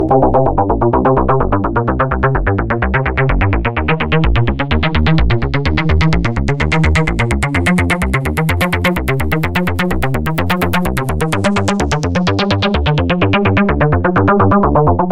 描述：又一个低音
标签： 合成器 循环 贝司 电子
声道立体声